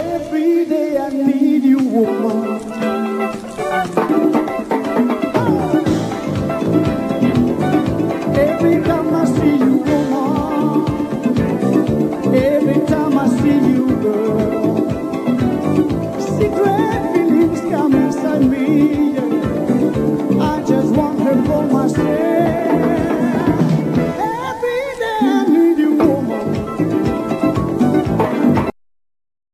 Video presentations of this 70s disco funk band: